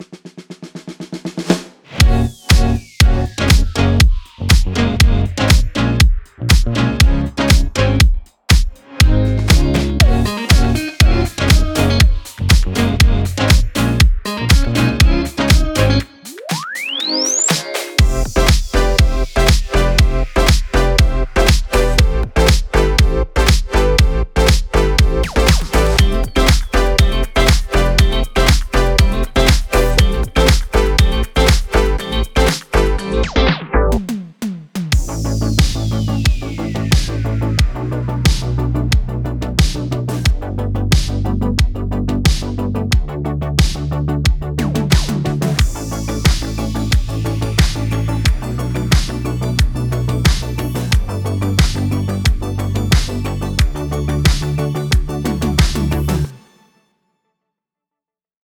Nu Disco和Synth Pop的声音
时髦的合成器，模拟低音和声码器。
Synth Loops
Guitar Loops
Drums
Vocals